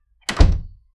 door-opened--p2wfew67.wav